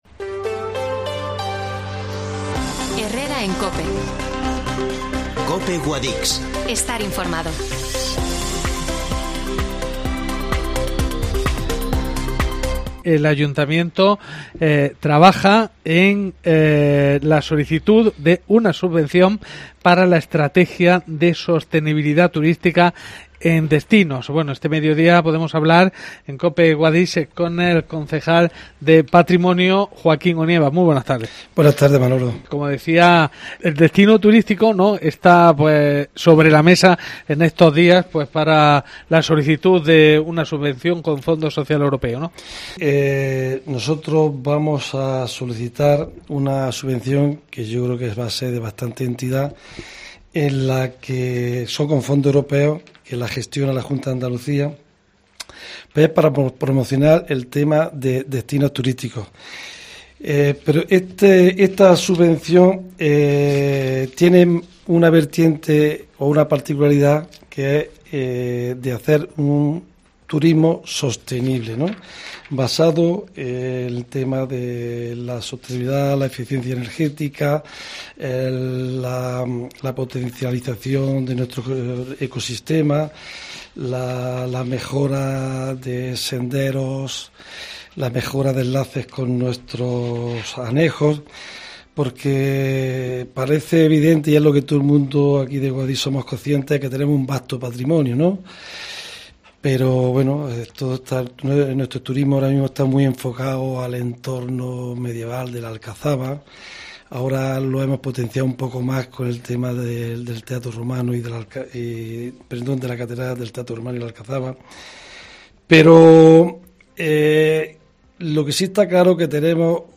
Joaquin Onieva Concejal de Patrimonio